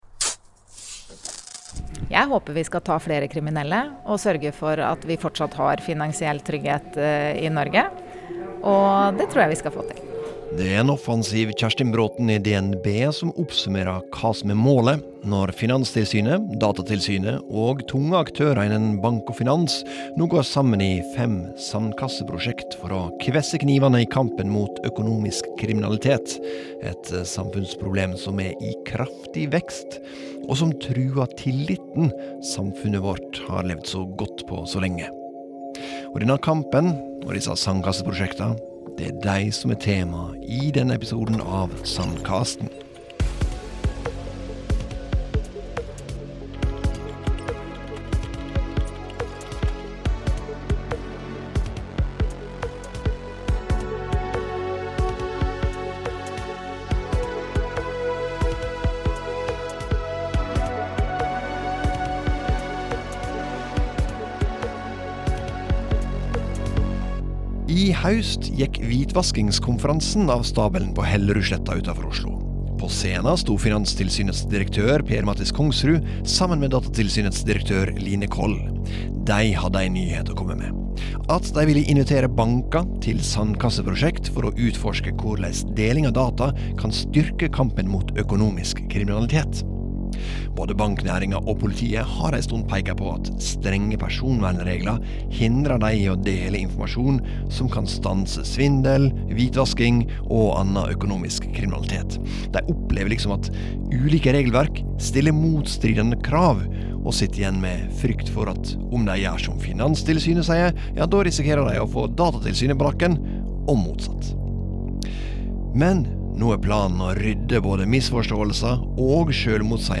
intervjuet Datatilsynets direktør Line Coll, Finanstilsynets direktør Per Mathis Kongsrud og DNBs konsernsjef Kjerstin Braathen